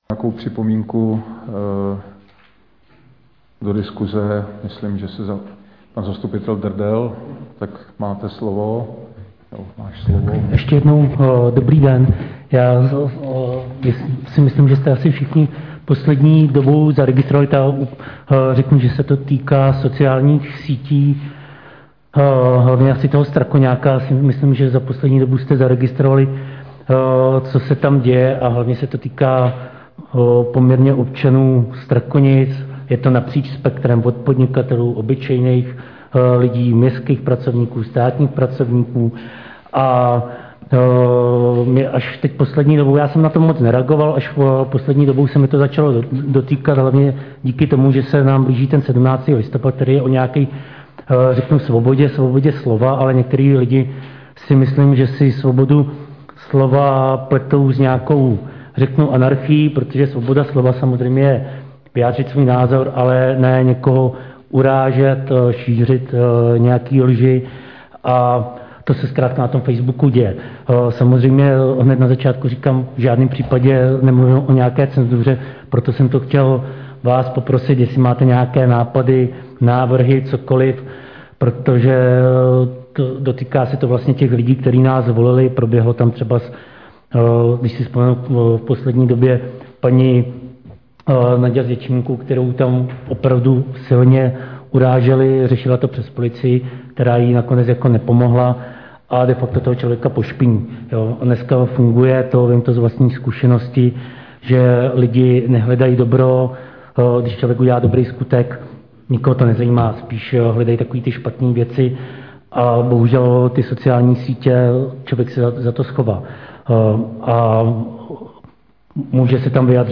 Záznam jednání